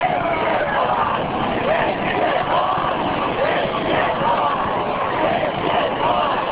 Suddenly, the President Michal Kovac appeared, and the eight-thousands crowd clapped hands and shouted "